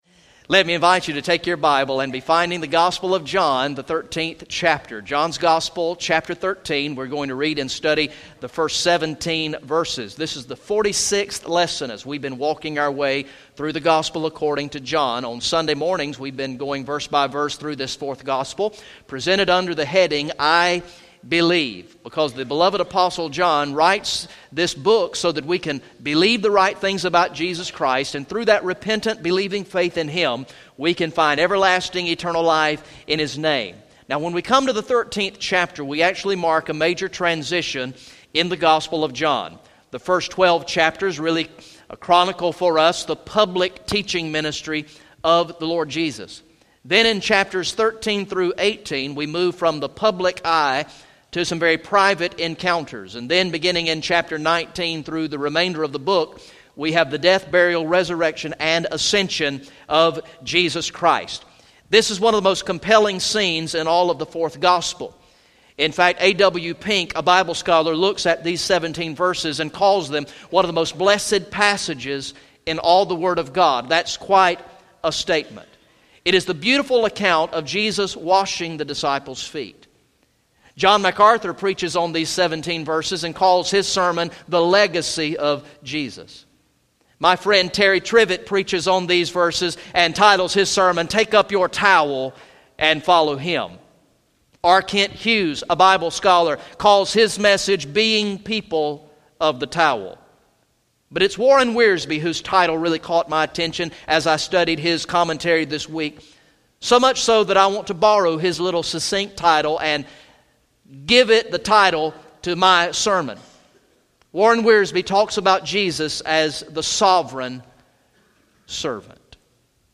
Message #46 from the sermon series through the gospel of John entitled "I Believe" Recorded in the morning worship service on Sunday, October 25, 2015